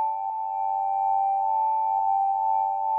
powerup.wav